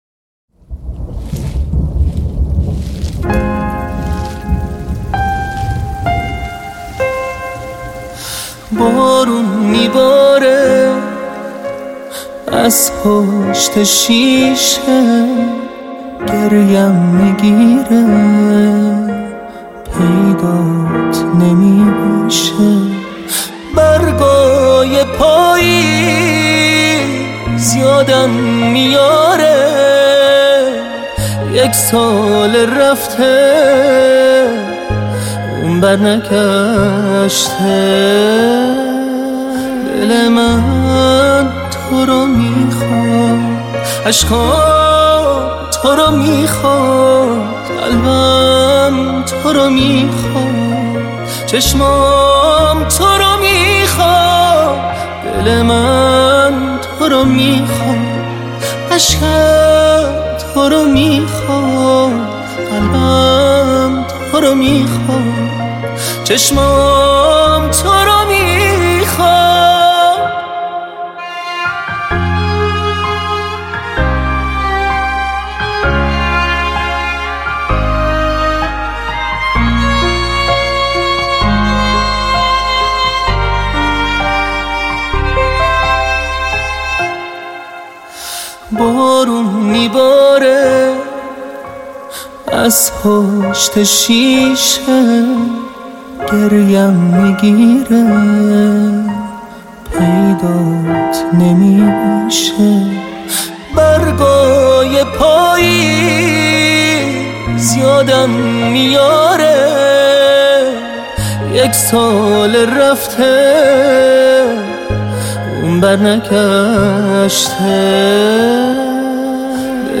پاپ / غمگین /